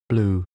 3. blue (adj., n.) /blu:/ xanh, màu xanh